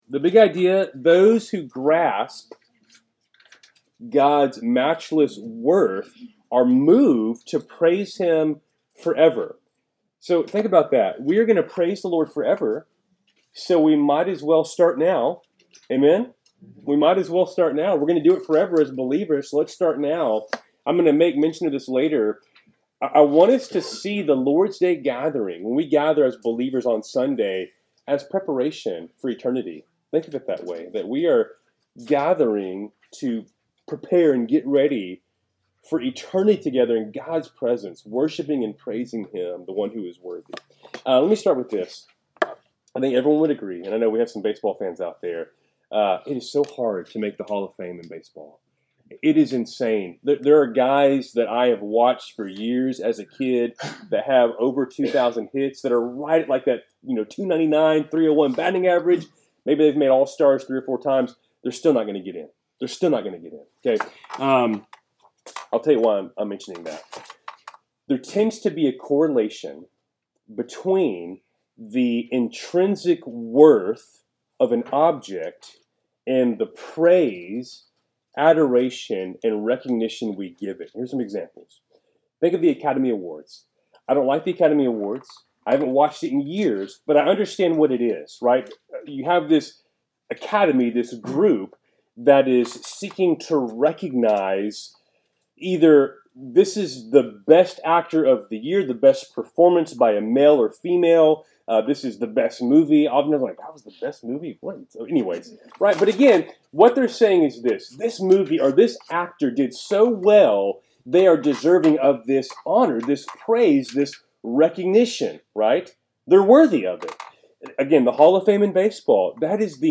Wednesday Night Bible Study, January 8, 2025